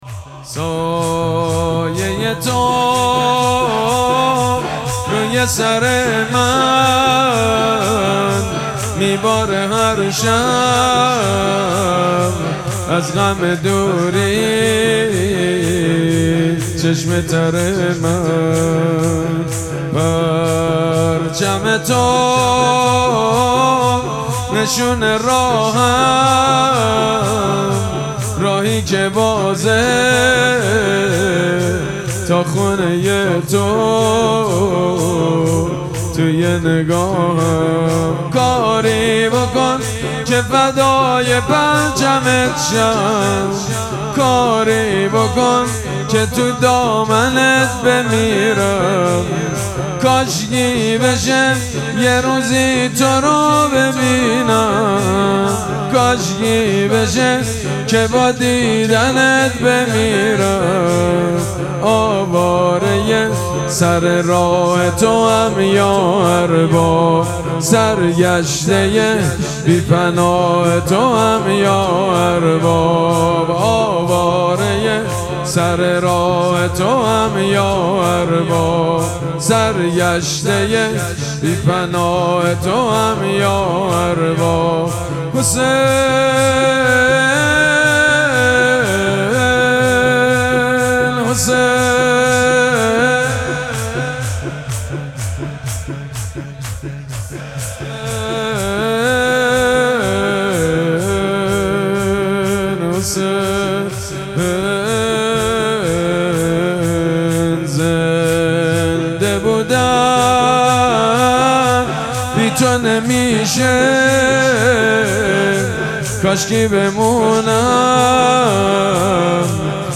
مراسم مناجات شب هشتم ماه مبارک رمضان
شور
حاج سید مجید بنی فاطمه